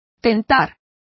Complete with pronunciation of the translation of entice.